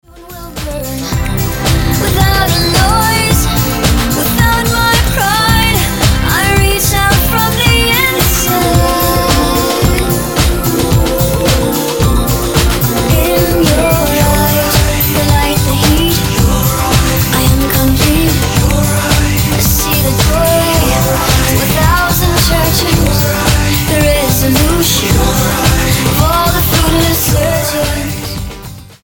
Gospel-tinged pop from a US singer
Pop Album